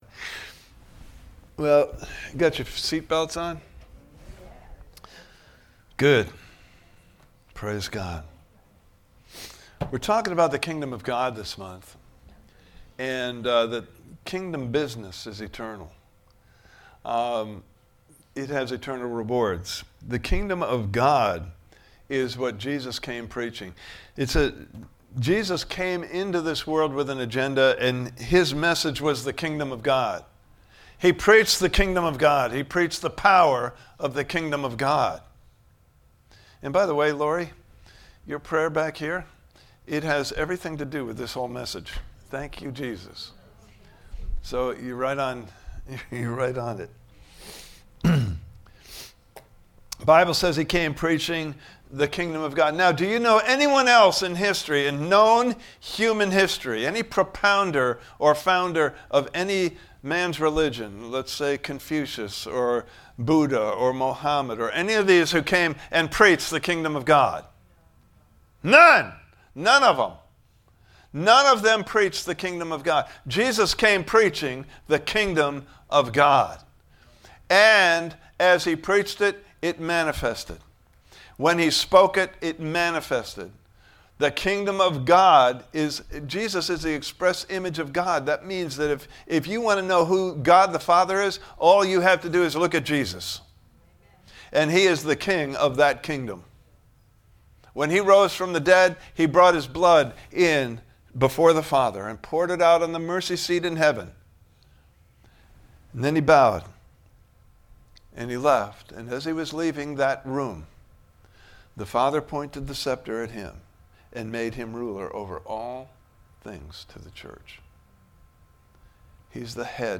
KINGDOM BUSINESS IS ETERNAL Service Type: Sunday Morning Service « Part 2